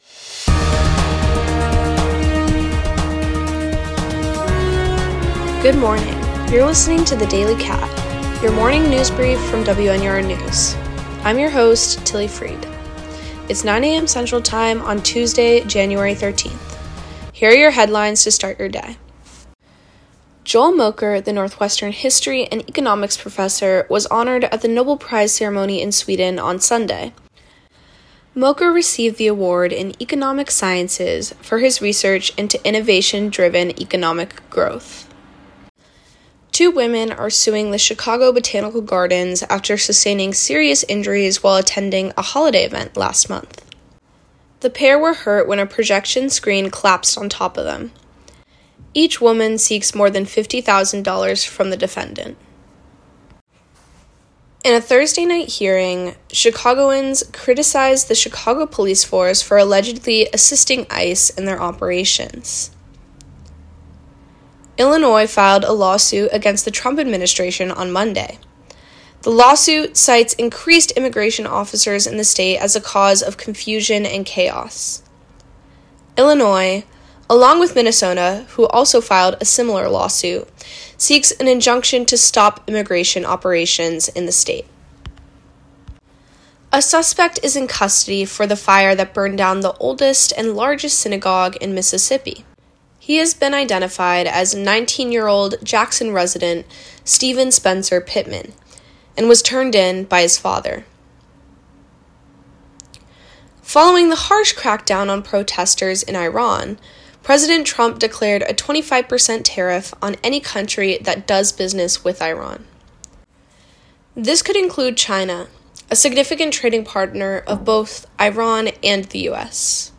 January 13, 2026: Iran, ICE, Chicago Botanical Gardens, Synagogue Fire. WNUR News broadcasts live at 6 pm CST on Mondays, Wednesdays, and Fridays on WNUR 89.3 FM.